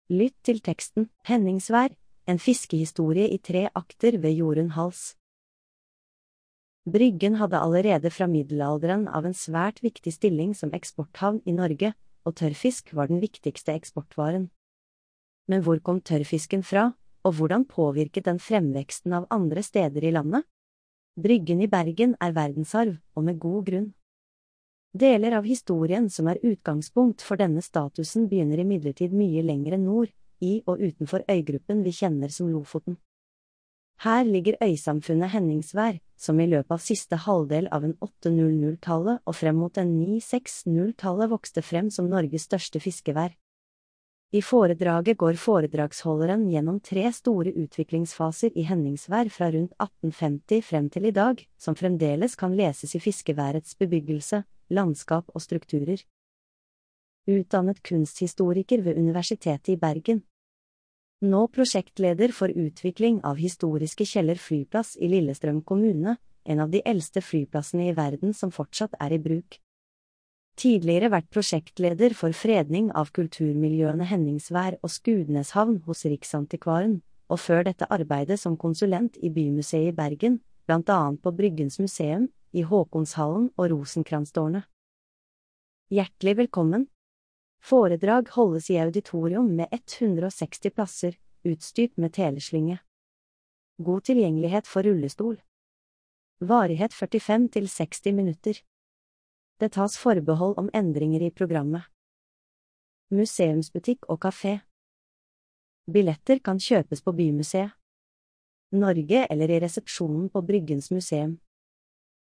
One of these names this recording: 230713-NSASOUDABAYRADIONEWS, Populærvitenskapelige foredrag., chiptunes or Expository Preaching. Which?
Populærvitenskapelige foredrag.